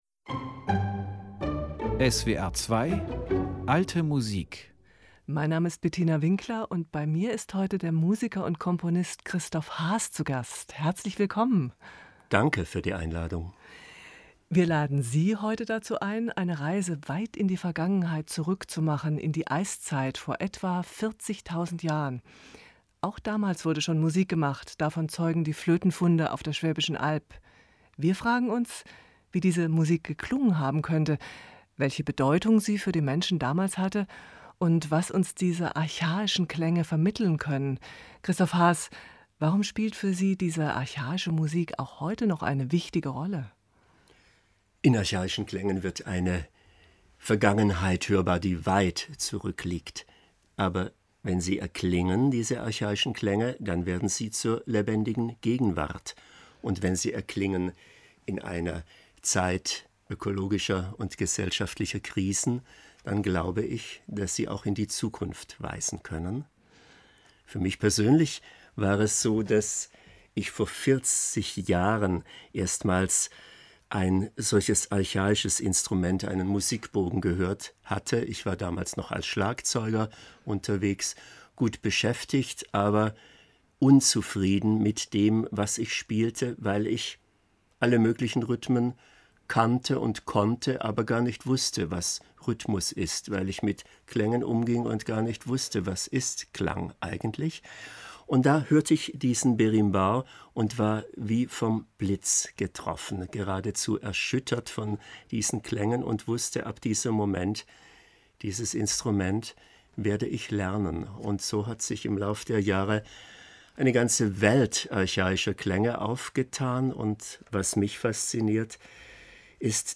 Alte Musik_Musik aus der Eiszeit.mp3